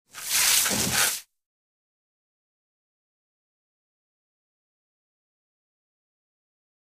Hose, Spray
Short Water Spray Against Hollow Surface